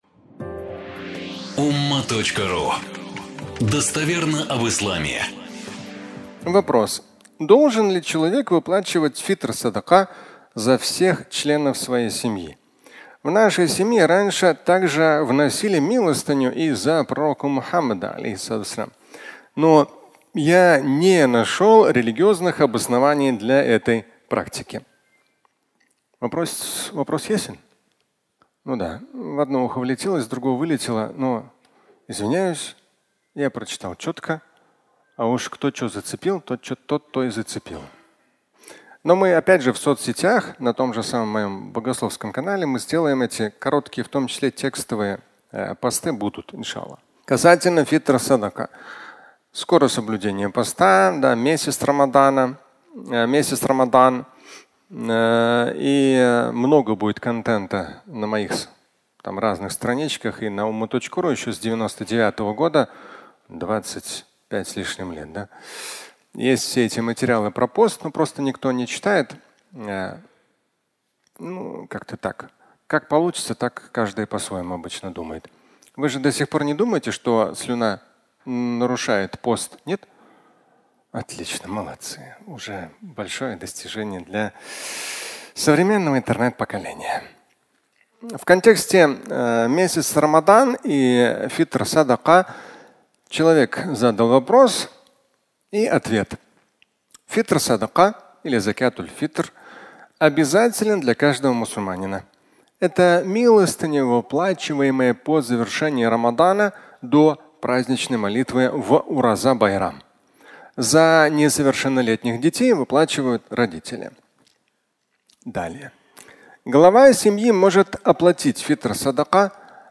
Милостыня за Пророка (аудиолекция)
Фрагмент пятничной лекции